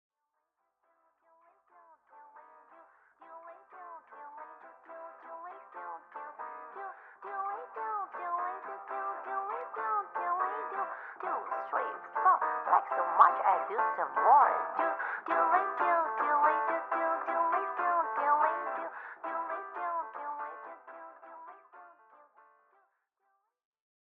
Recorded on Dec. 22nd and 23rd, 2024 at Studio Dede, Tokyo